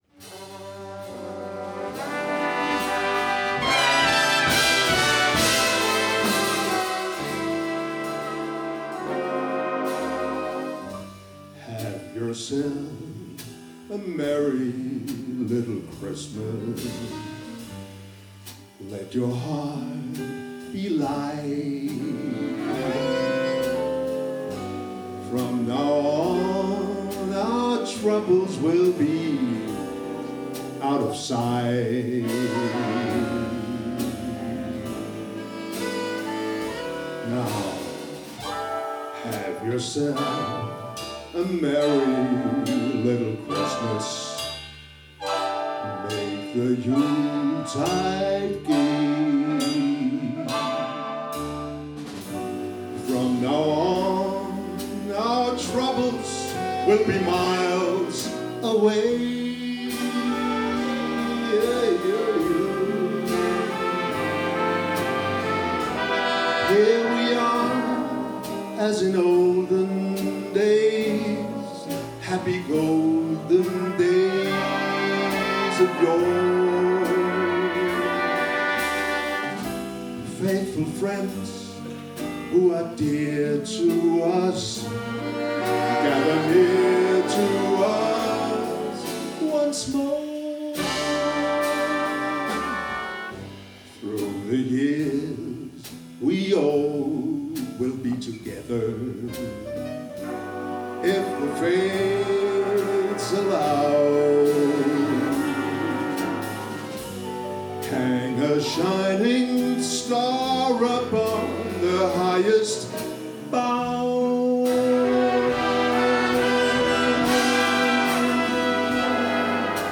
Vi garanterer, at I kommer i julestemning når I hører de jazzsvingende juleklassikere, flere af dem med vokal-indslag.
Udstyret er én digital stereo mikrofon, ikke en studieoptagelse !